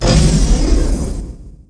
sounds_spacewar_weapons.dat
1 channel